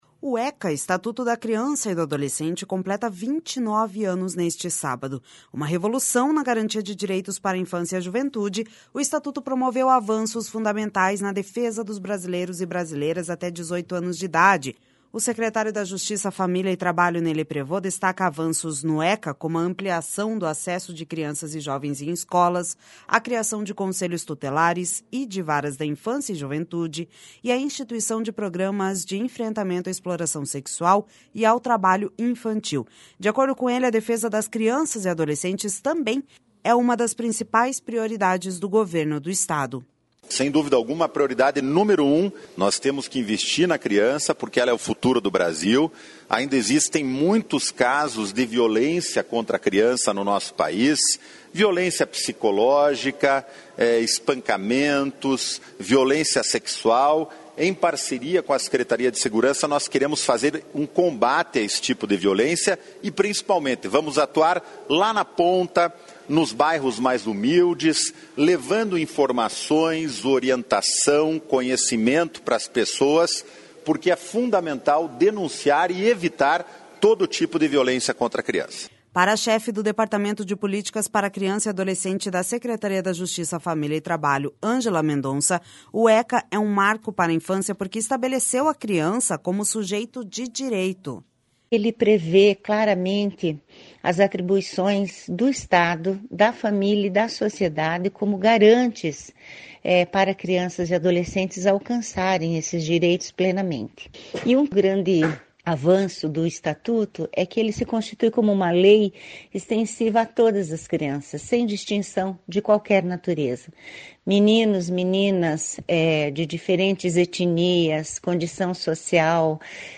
O secretário da Justiça, Família e Trabalho, Ney Leprevost, destaca avanços no ECA, como a ampliação do acesso de crianças e jovens em escolas, a criação de Conselhos Tutelares e de Varas da Infância e Juventude, e a instituição de programas de enfrentamento à exploração sexual e ao trabalho infantil.